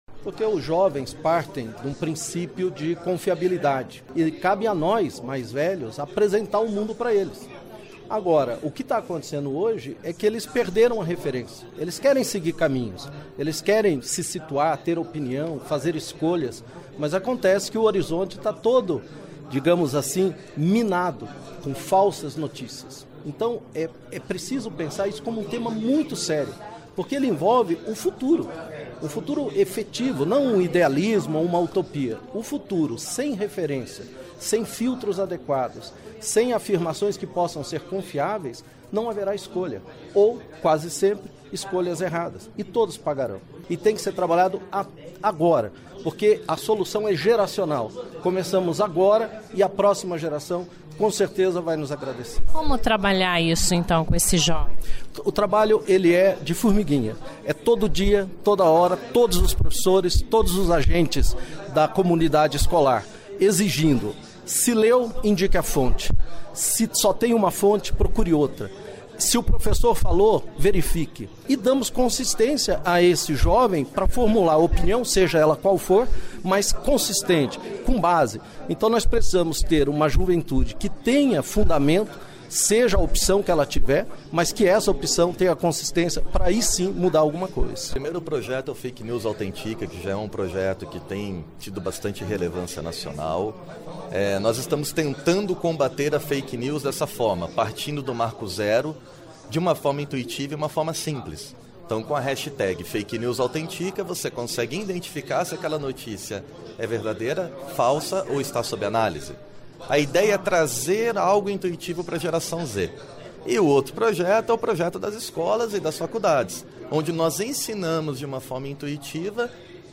Por último, a entrevista é com o deputado Felipe Francischini (SD), que trouxe os convidados para falarem do tema, e que lembra como é de fundamental importância o combate às notícias falsas  nas redes sociais e em ano eleitoral.